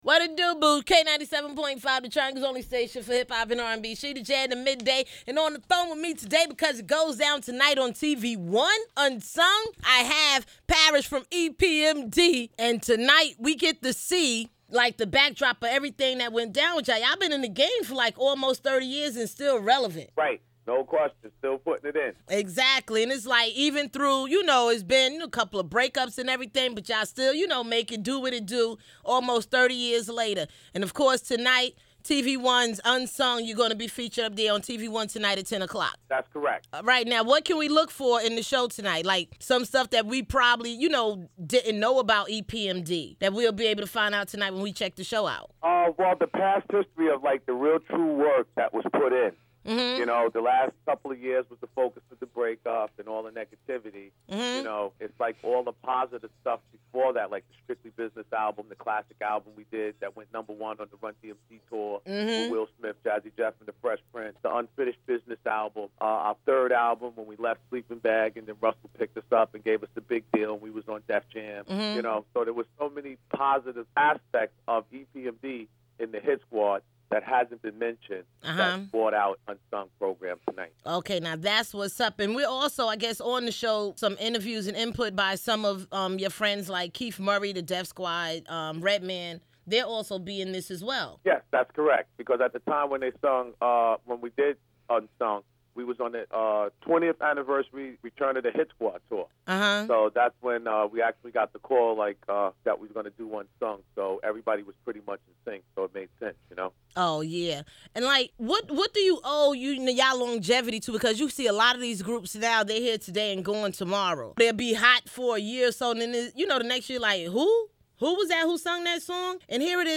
With almost 30 years in the game, Eric Sermon and Parrish Smith better known as EPMD are still Making Dollars. Check out my interview with Parrish Smith and get ready to get Unsung with EPMD tonight at 10pm on TV ONE…..EPMD […]
epmd-interview.mp3